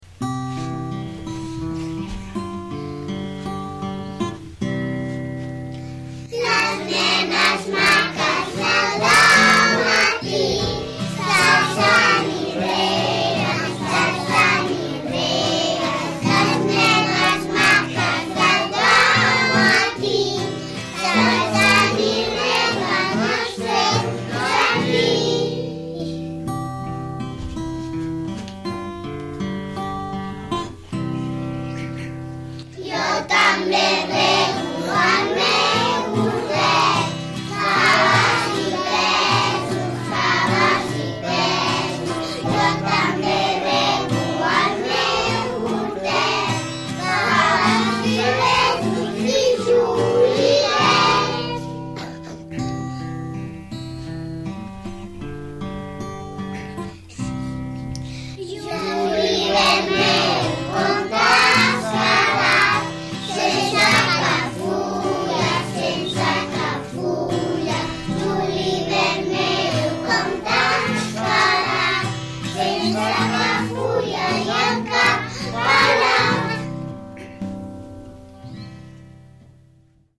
Avui hem anat a la ràdio a gravar la cançó de les nenes maques al dematí.
podreu escoltar els nens i nenes del parvulari cantant